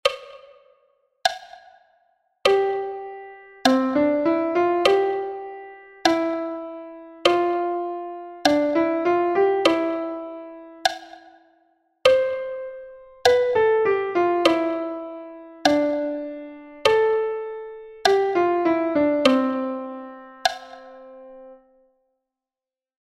Number one and four have got the metronome sound to help you.
1_con_metronomo.mp3